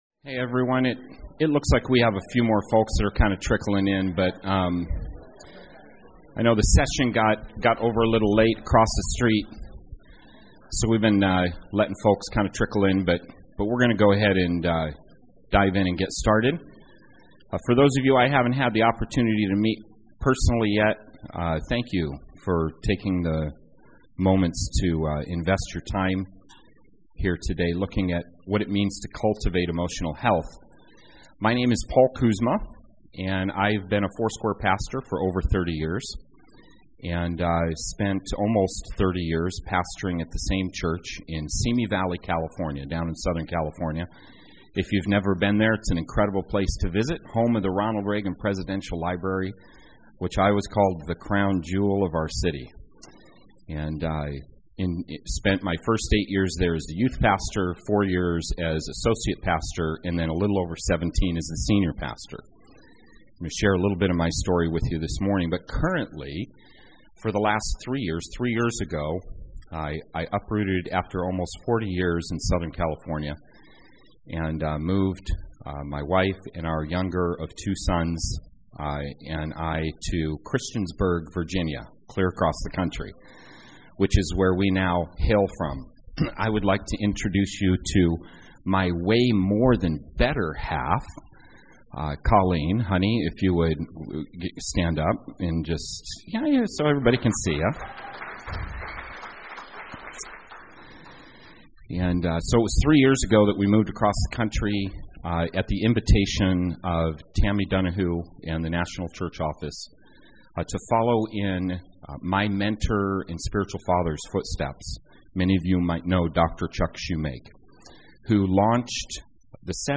in this live recording from Foursquare Connection 2018.